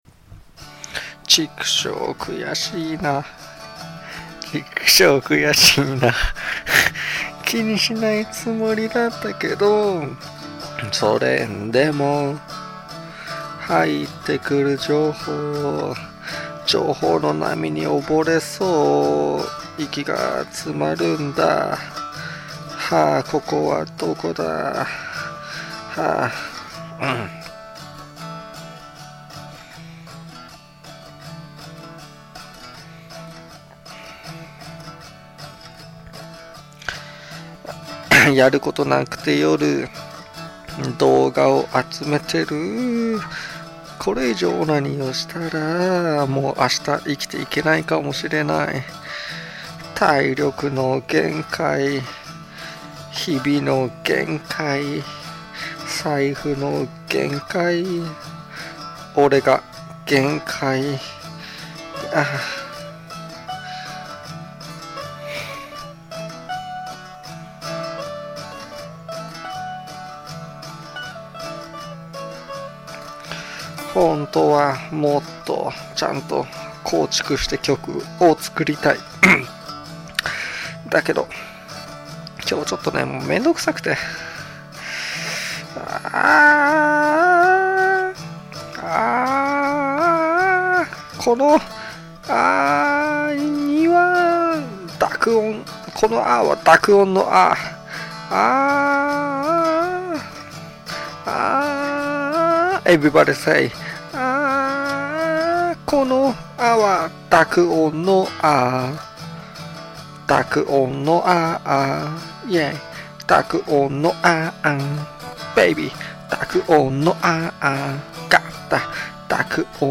【ジャンル】 ロック
今回は咳も入ってしまいました。
アドバイスを参考に最後はシャウトしてみました。